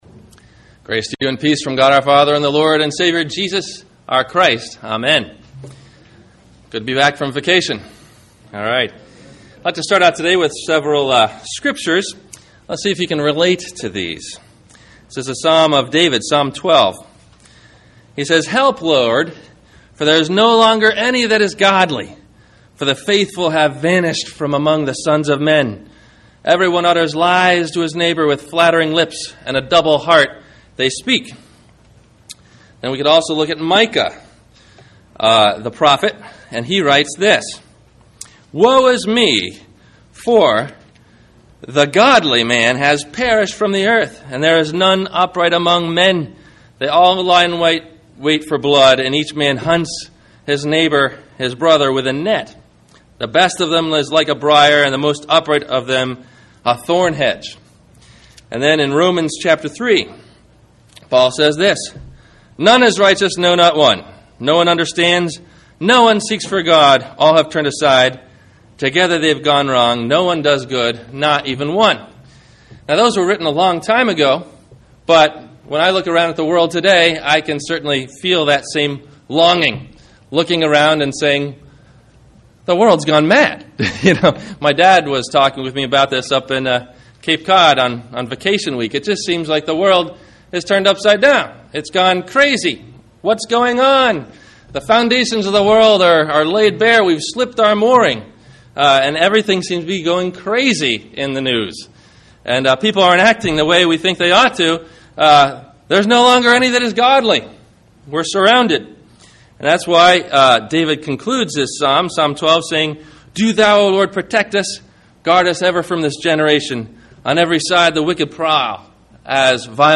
The Golden Age – The Good Old Days - Sermon - August 09 2009 - Christ Lutheran Cape Canaveral